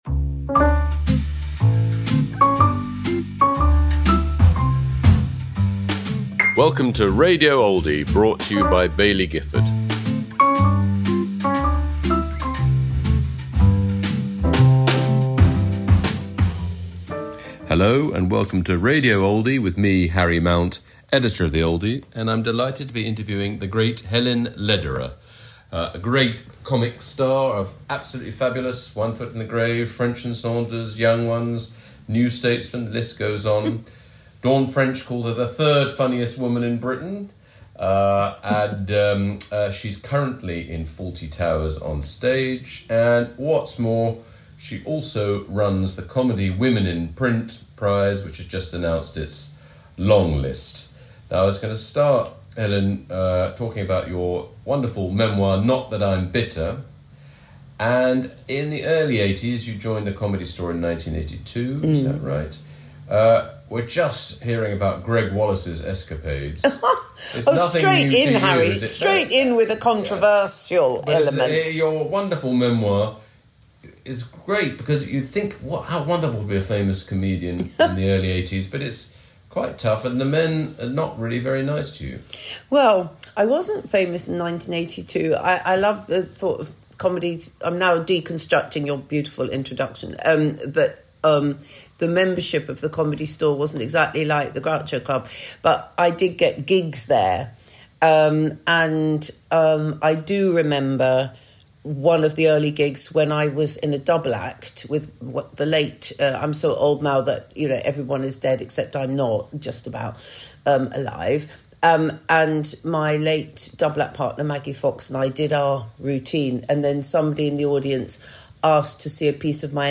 Helen Lederer in conversation with Harry Mount